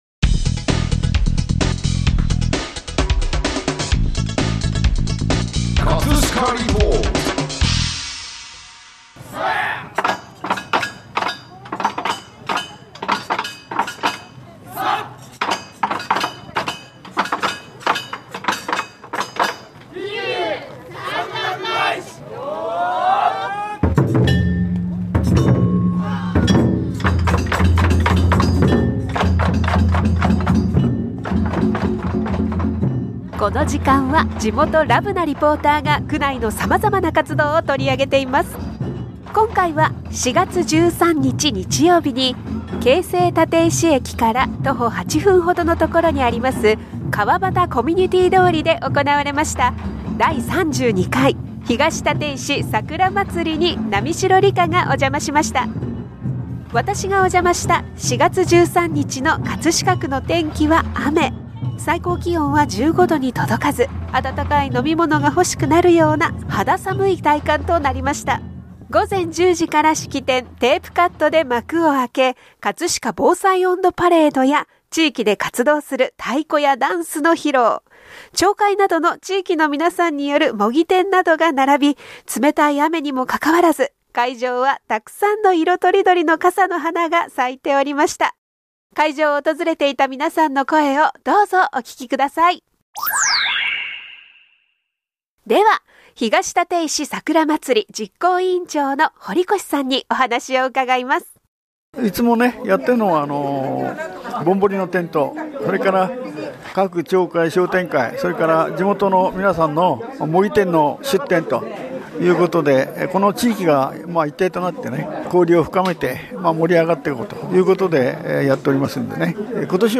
【葛飾リポート】 葛飾リポートでは、区内の様々な活動を取り上げています。
午前10時から式典、テープカットで幕を開け、葛飾防災音頭パレードや地域で活動する太鼓やダンスの披露、町会などの地域の皆さんによる模擬店などが並び、冷たい雨にもかかわらず、沢山の色とりどりの傘の花が咲いておりました。 会場を訪れていた皆さんの声をどうぞお聴き下さい！